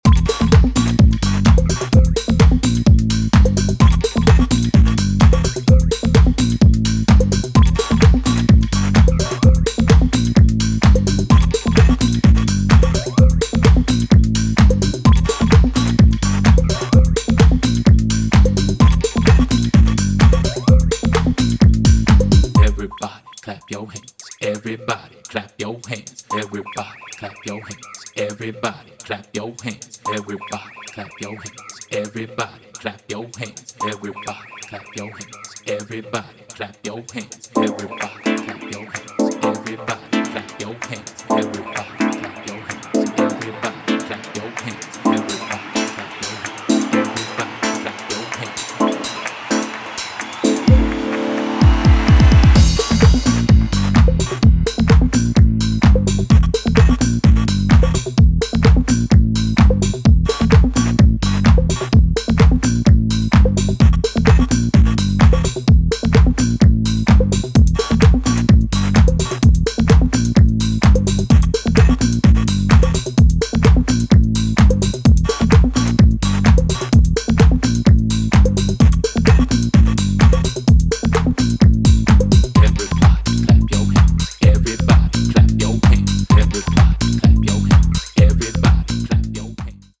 two bright, energetic tracks